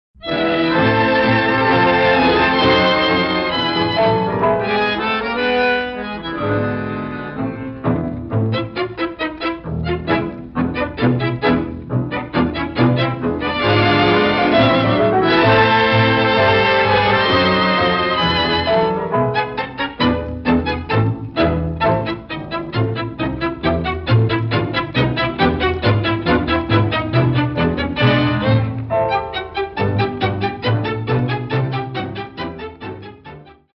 Klassische Tangos (wo ist die Grenze?)